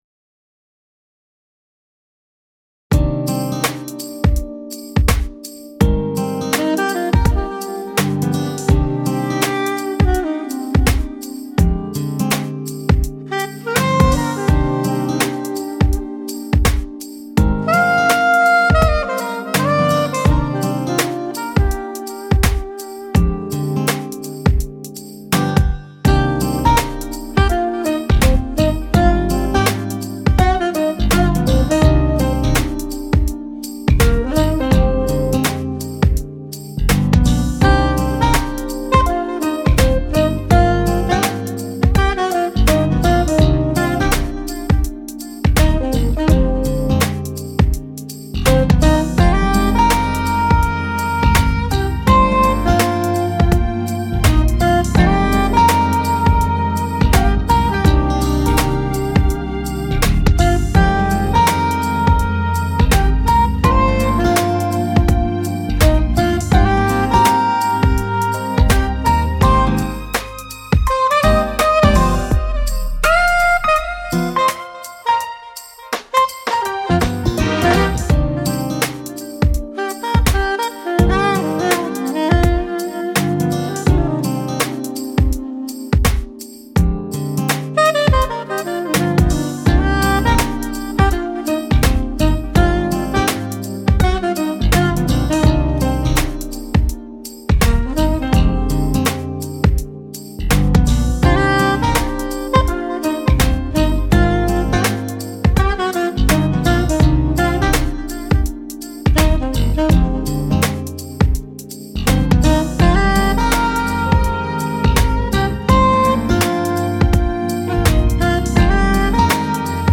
The music concept blends Jazz Latin Fusion with elements of dance, pop, hip-hop, R&B, and reggae funk.